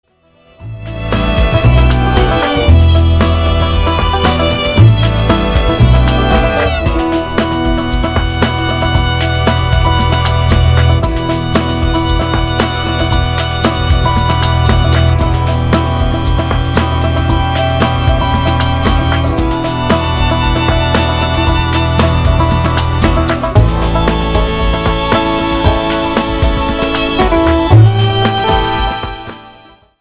COUNTRY ROCK  (3.12)